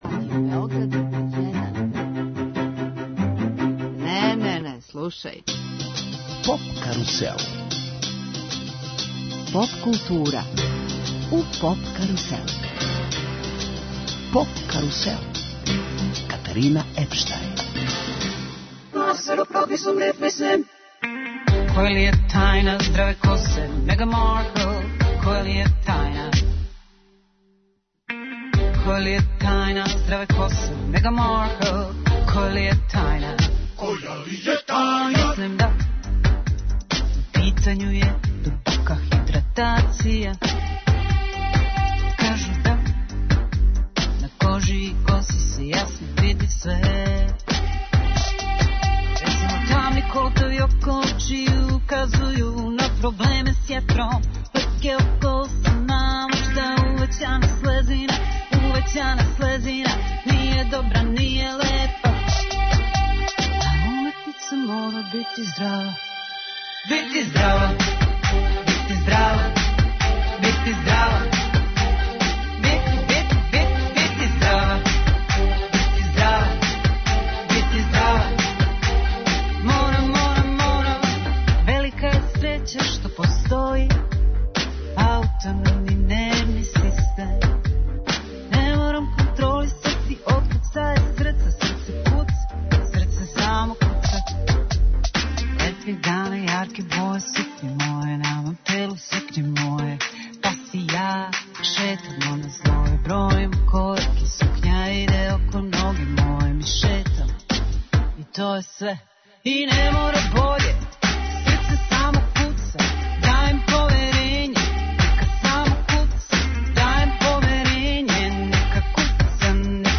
Емисија 'Поп карусел' је посвећена Евросонгу а музиком подсећамо на победнике, највеће хитове и домаће представнике, највећег европског музичког такмичења.